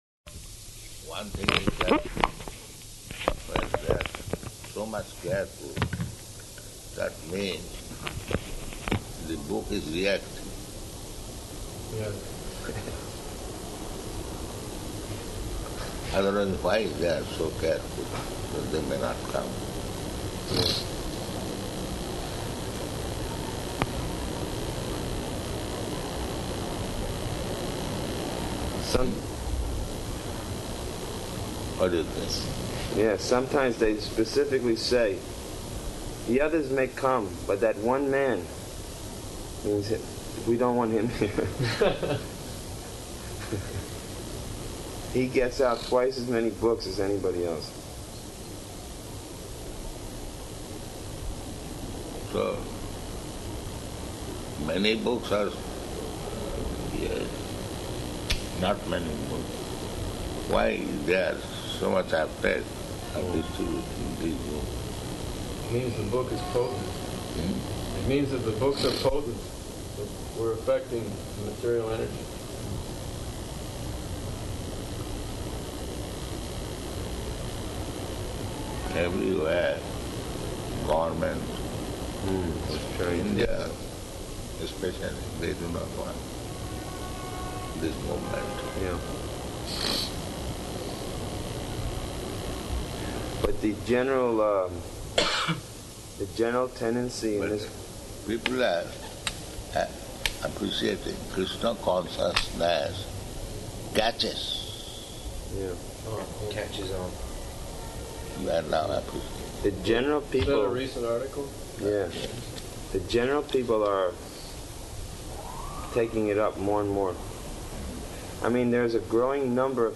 Room Conversation
Room Conversation --:-- --:-- Type: Conversation Dated: July 10th 1976 Location: New York Audio file: 760710R1.NY.mp3 Prabhupāda: So one thing is that when they are so much careful, that means the book is reacting.